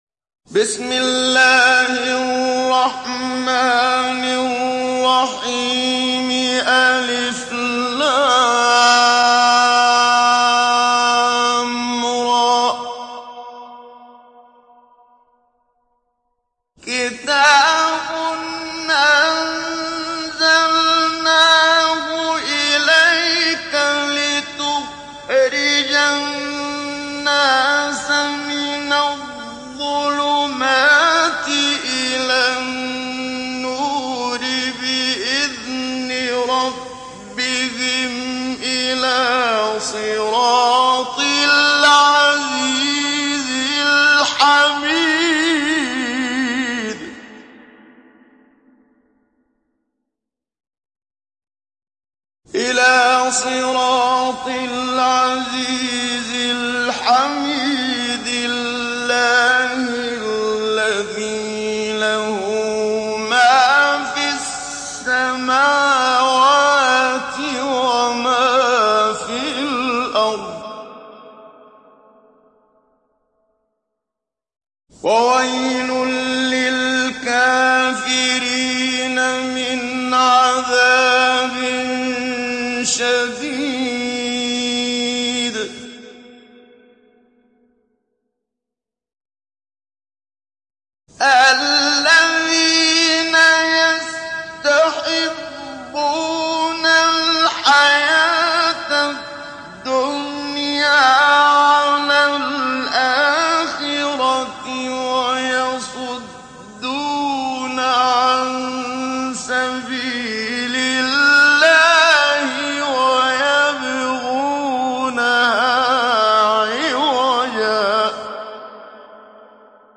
ডাউনলোড সূরা ইব্রাহীম Muhammad Siddiq Minshawi Mujawwad